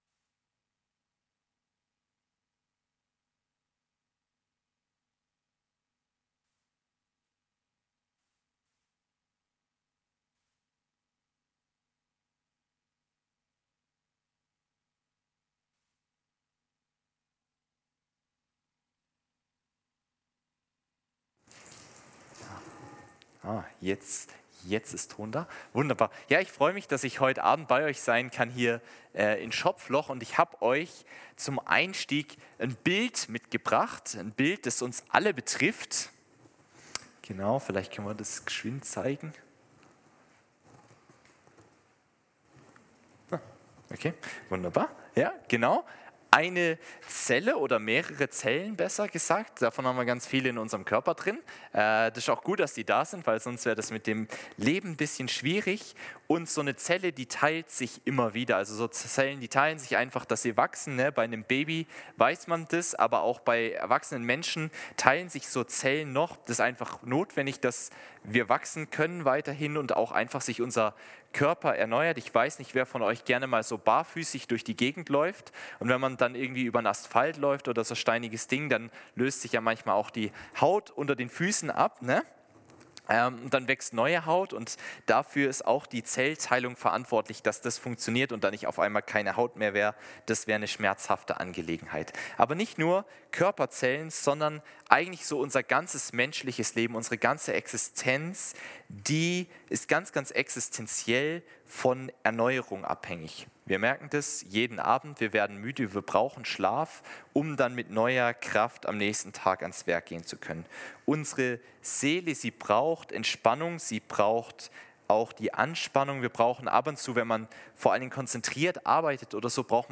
Gottesdienst am 07.07.2024